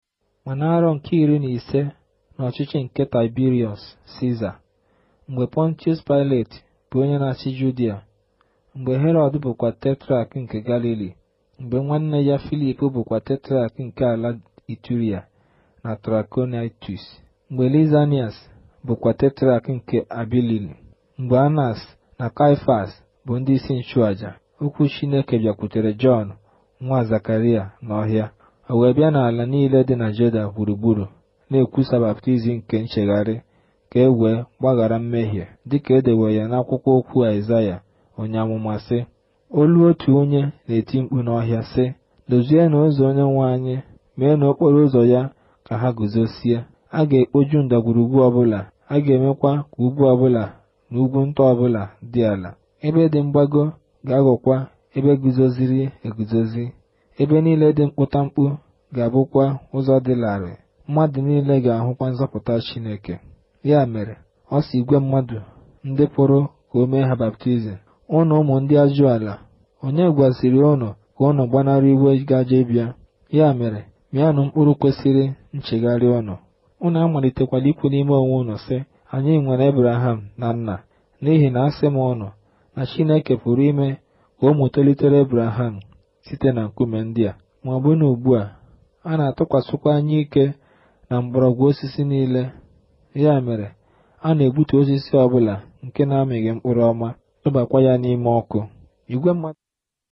Here’s a recording in a mystery language.